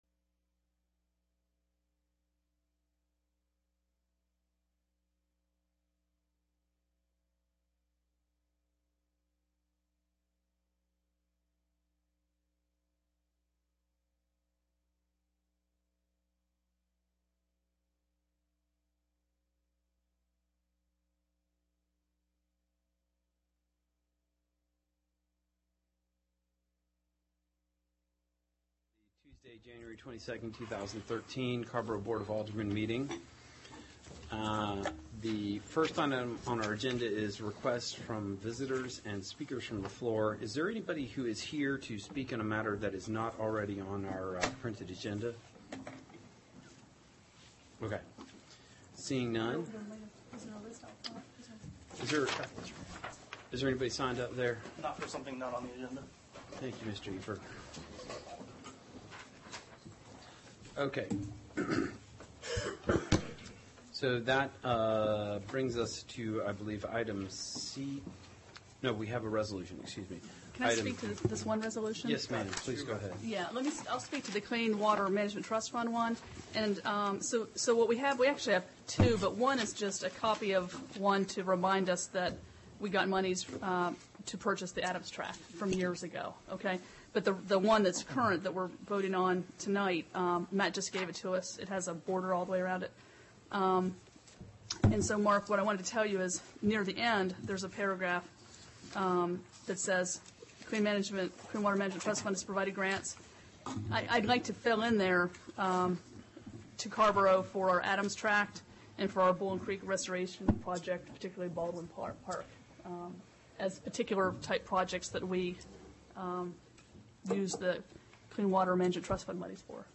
AGENDA CARRBORO BOARD OF ALDERMEN REGULAR MEETING* Tuesday, January 22, 2013 7:30 P.M., TOWN HALL BOARD ROOM
*Please note that public comment is limited to three minutes per speaker.